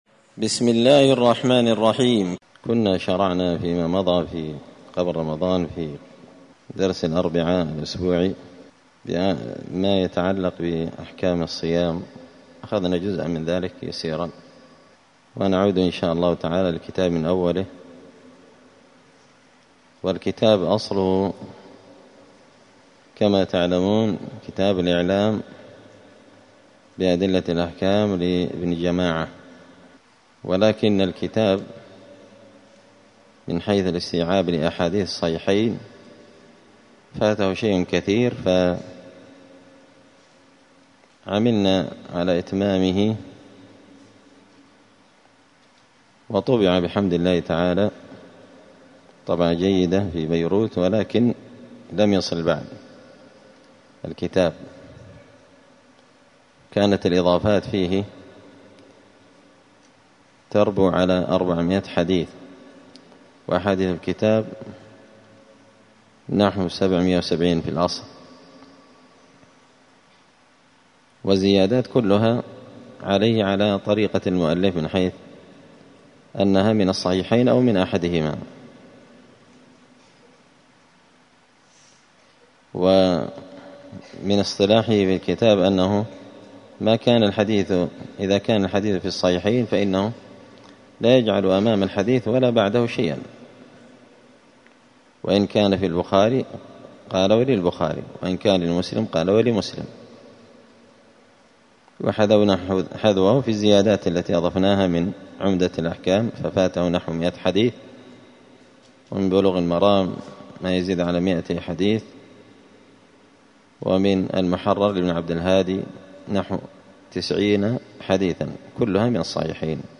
دار الحديث السلفية بمسجد الفرقان بقشن المهرة اليمن
*الدرس الأول (1) {مقدمة الكتاب…}*